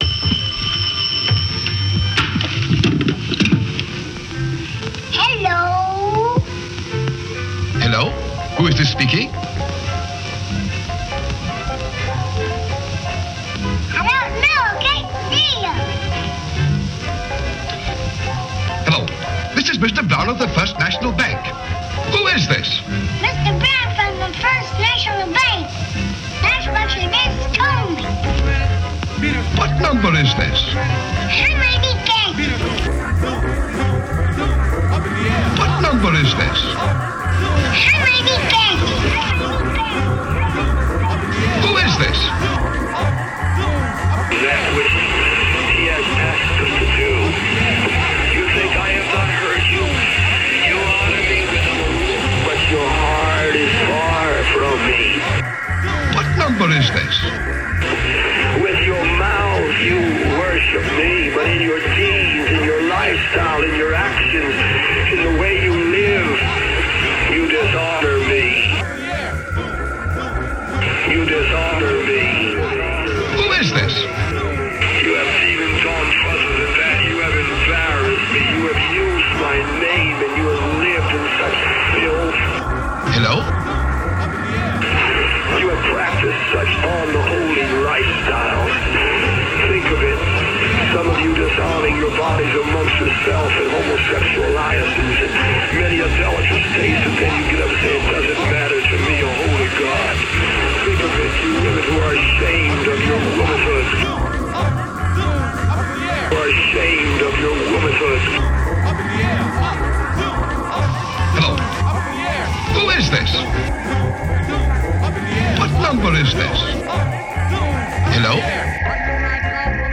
Recorded at Cyclops’ Lair, Middleburg Heights, OH.